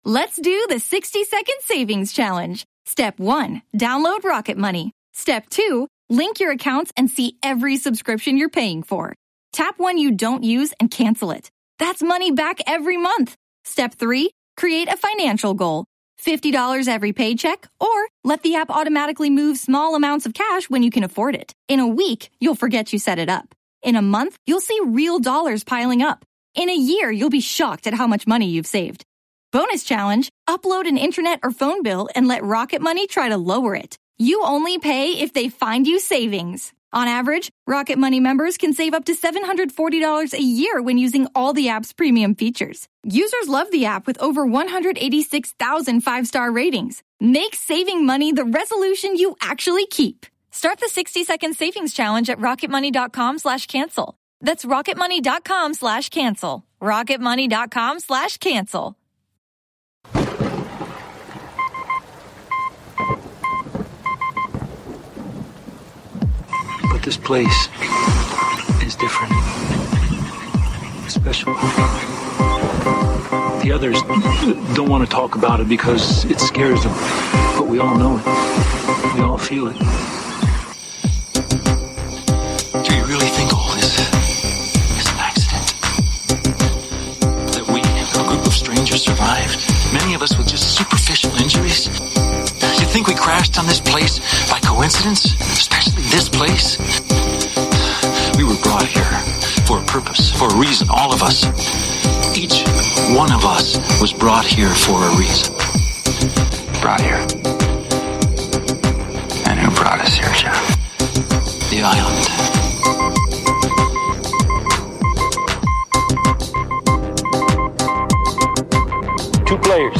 THE INTERVIEW (No Spoilers) - 55 minutes and 28 seconds THE STORM (SPOILERS!)